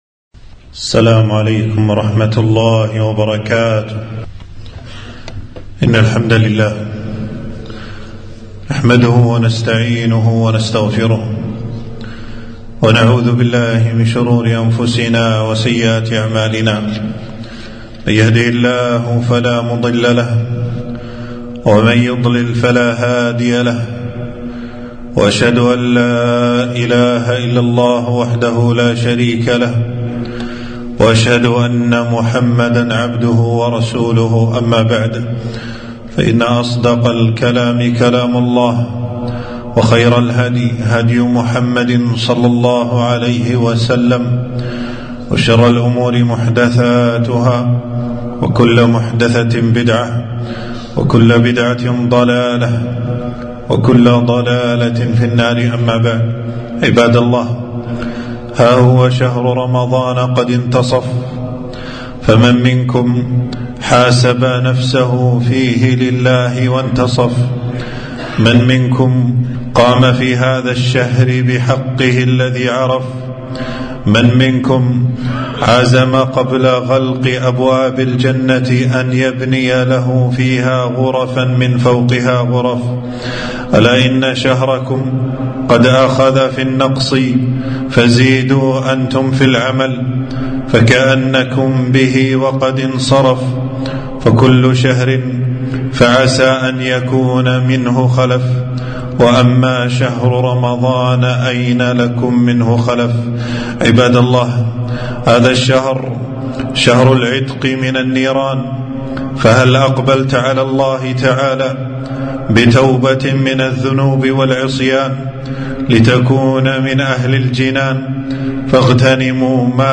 خطبة - ها قد دخل النصف من رمضان فهل تبت إلى الرحمن